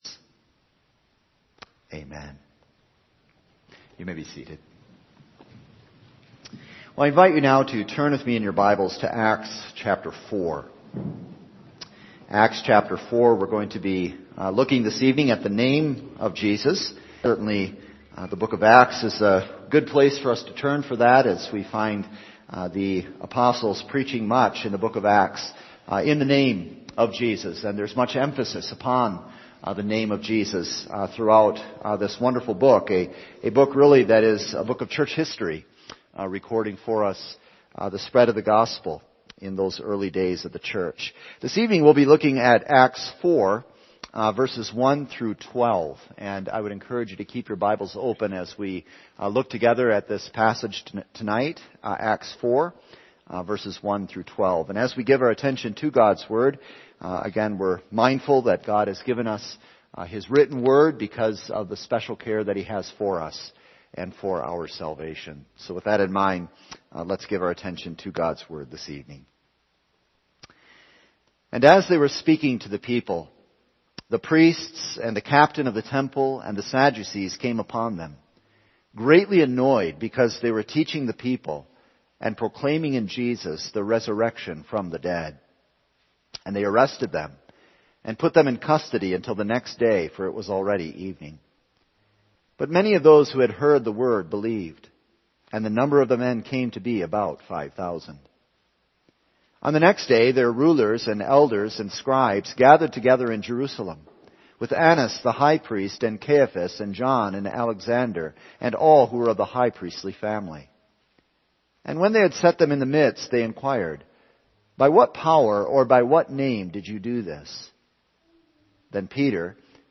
All Sermons No Other Name April 24